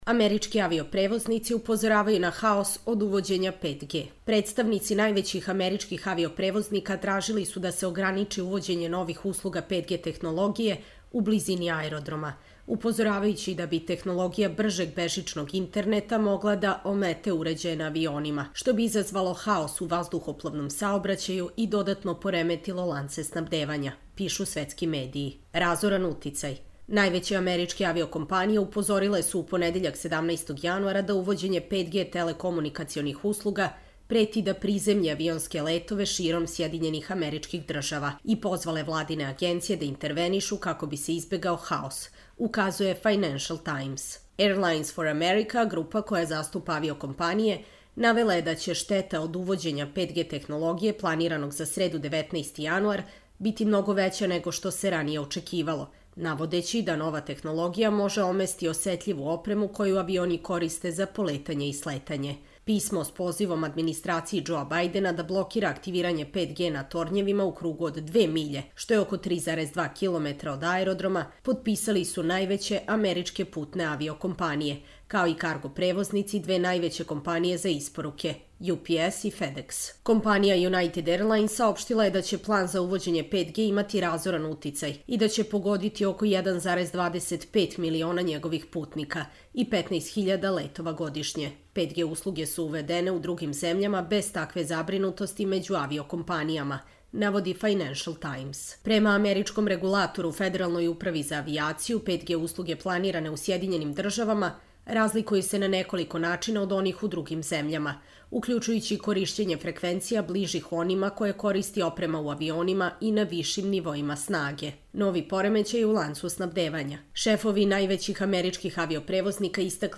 Čitamo vam: Američki avio-prevoznici upozoravaju na haos od uvođenja 5G